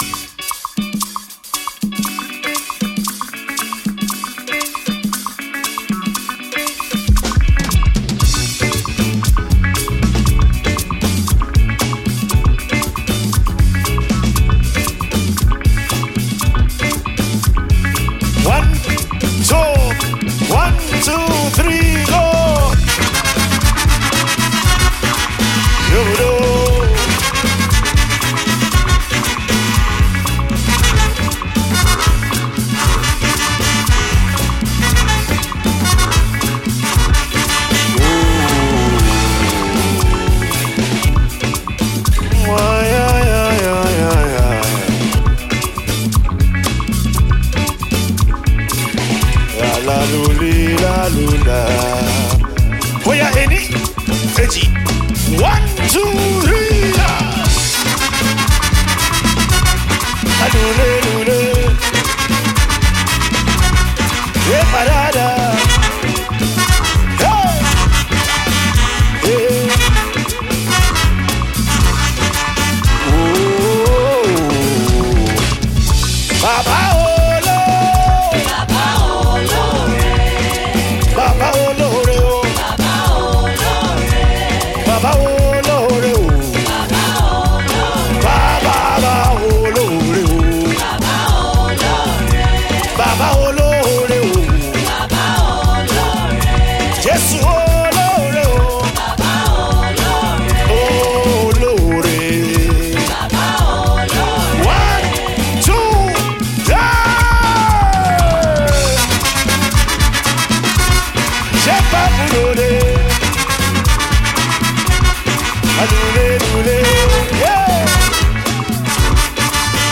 gospel
recorded live
African fusion mixed with highlife